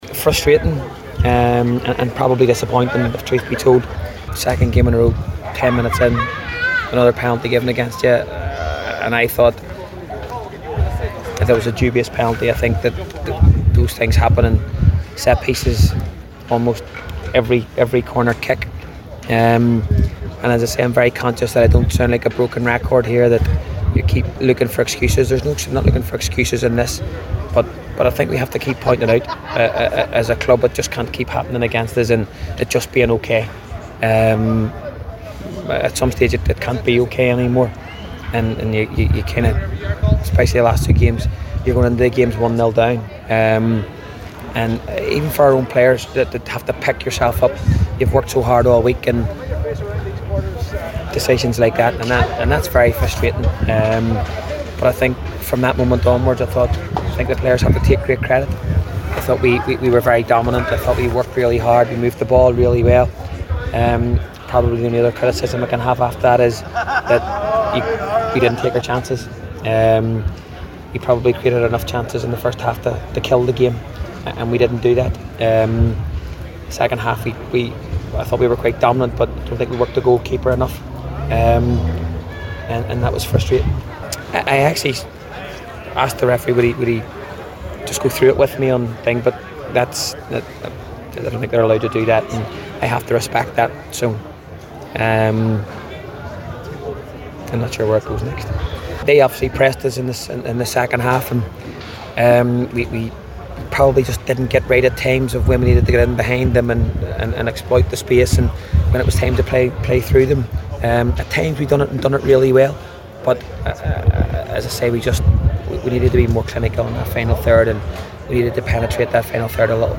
spoke to the assembled media after the game…